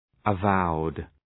Shkrimi fonetik {ə’vaʋd}